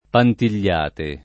[ pantil’l’ # te ]